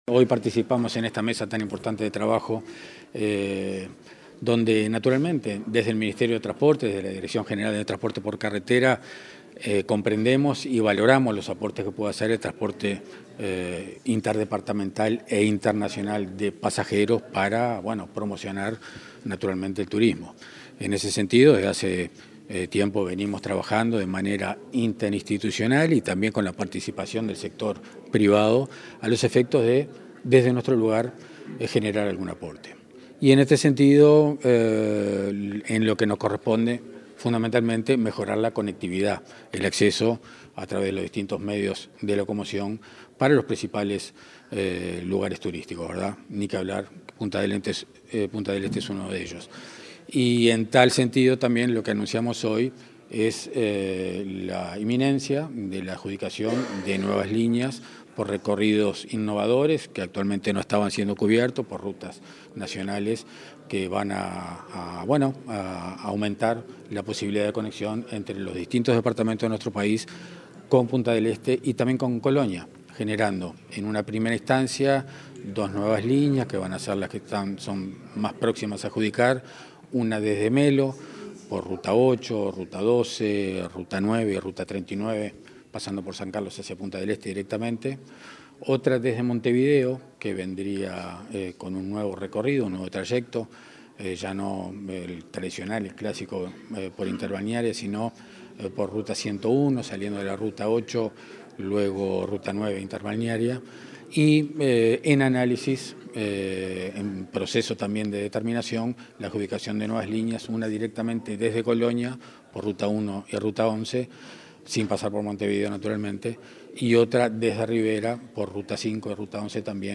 Declaraciones de Carlos Flores, del MTOP
Declaraciones de Carlos Flores, del MTOP 25/07/2022 Compartir Facebook X Copiar enlace WhatsApp LinkedIn El director de Transporte por Carretera, del Ministerio de Transporte y Obras Públicas (MTOP), Carlos Flores, participó el pasado viernes 22 en una jornada de trabajo para coordinar acciones a fin de fomentar el turismo local, organizada por la Intendencia de Maldonado. Luego, dialogó con la prensa.